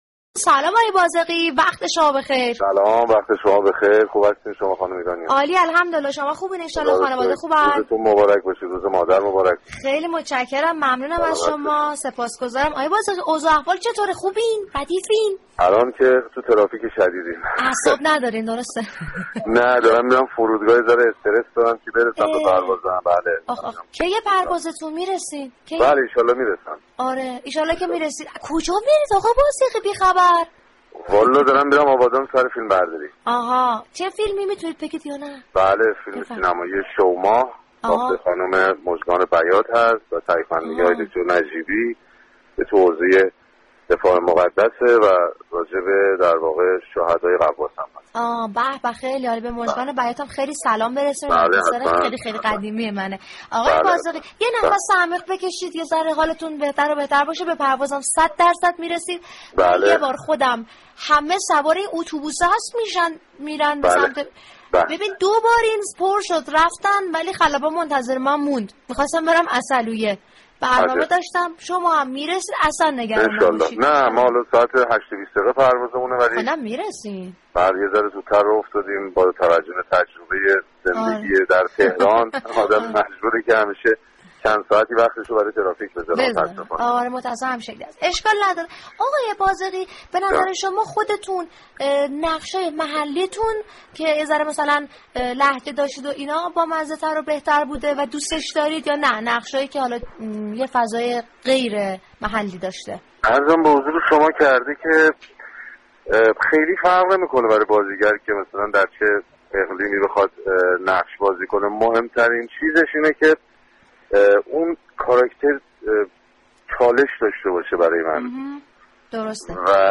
پژمان بازغی در گفتگو با «رادیو صبا » درباره بازیگری نقش های متفاوت صحبت كرد.
روز شنبه دوم بهمن پژمان بازغی بازیگر و مجری خوب تلویزیونی در ایستگاه سینما همسفر قطار صبا شد.